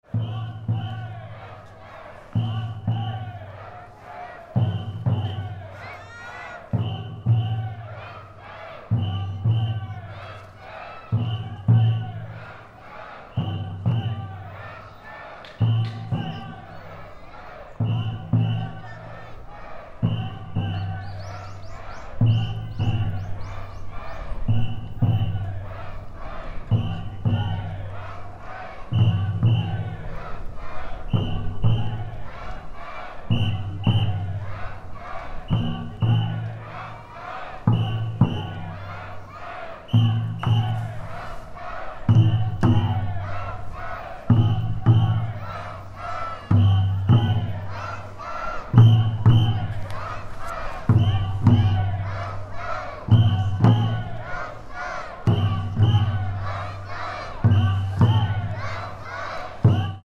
Fukushima Soundscape: Mt. Shinobu
The parade of O-Waraji (Big Japanese Sandal) passed through the road next to the fixed recording point at Mt. Shinobu.